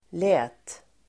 Uttal: [lä:t]